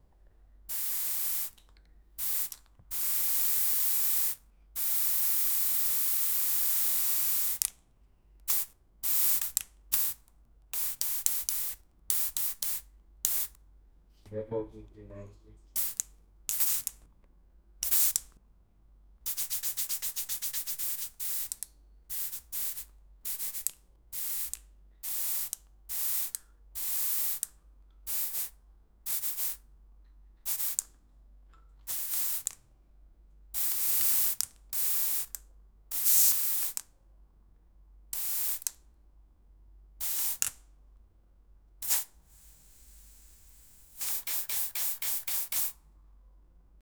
Non-stop aerosol spray painting, with constant spray can rattling together with continuous spraying
non-stop-aerosol-spray-pa-zf4tg6uo.wav